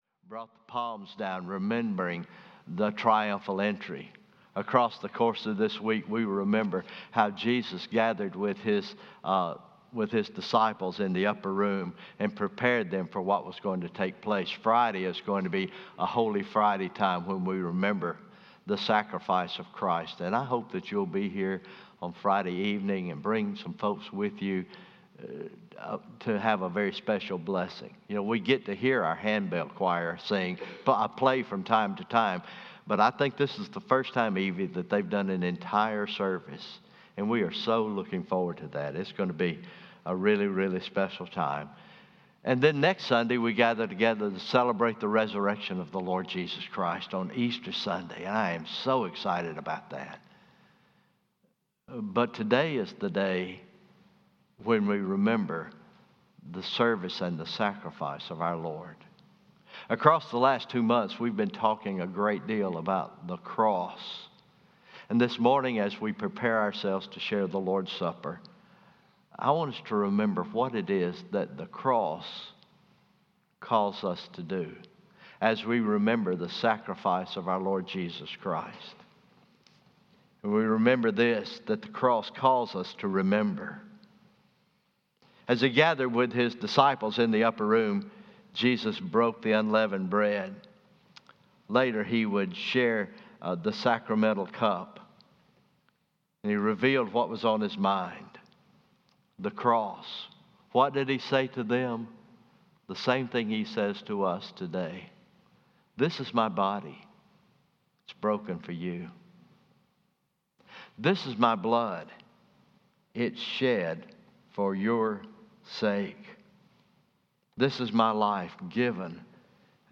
Communion Meditation